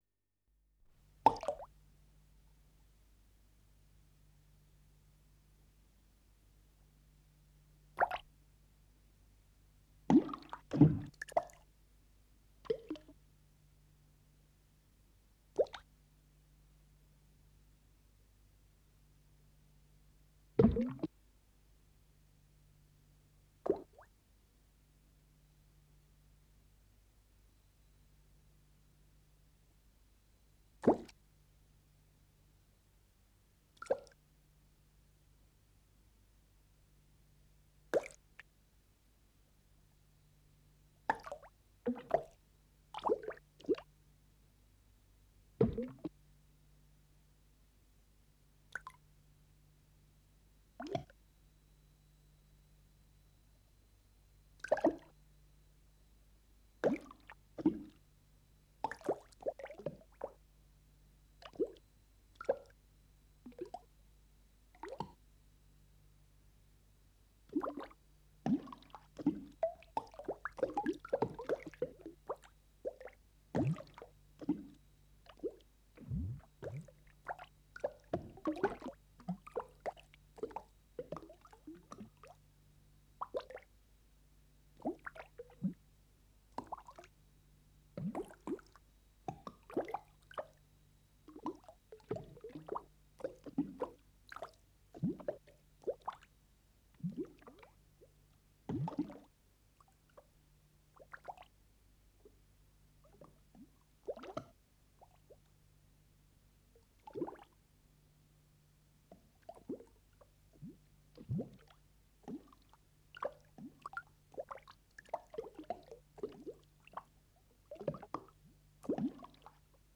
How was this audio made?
Production: Studio voor electronische muziek van de Rijksuniversiteit te Utrecht